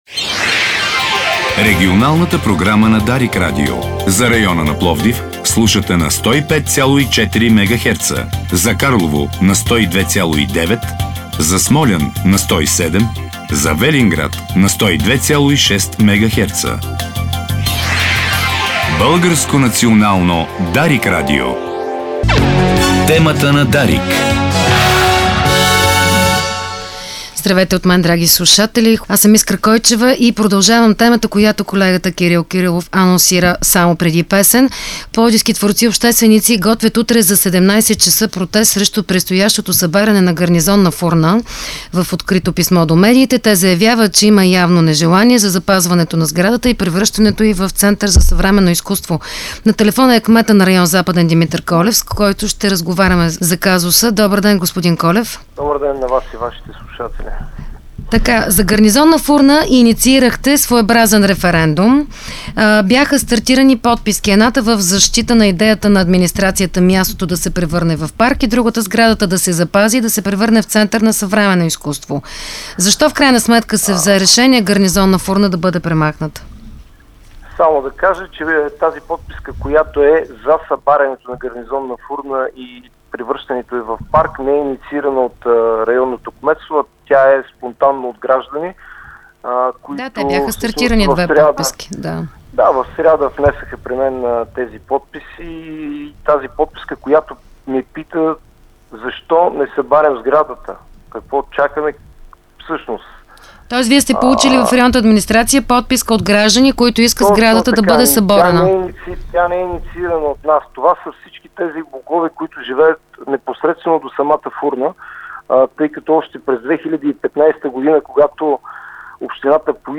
Старите постройки на Гарнизонна фурна ще бъдат съборени. Това съобщи в ефира на Дарик кметът на пловдивския район „Западен“ Димитър Колев.